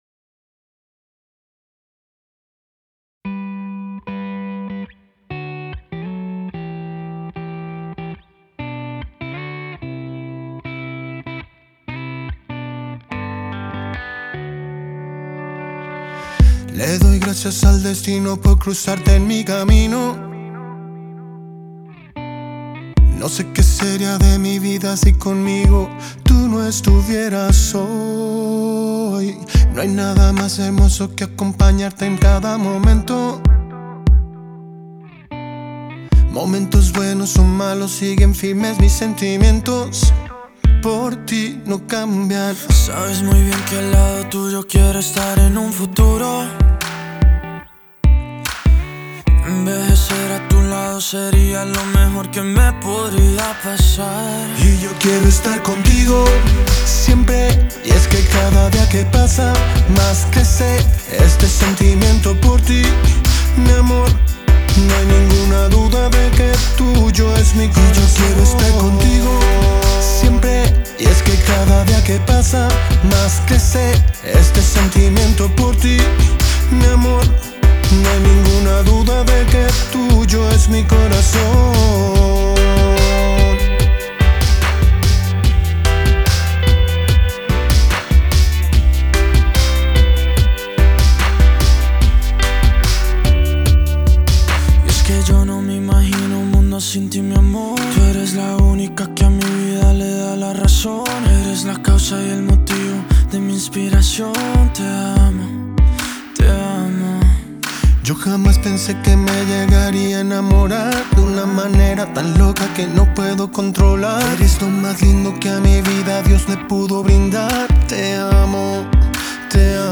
dueto
es una tema muy romántico y moderno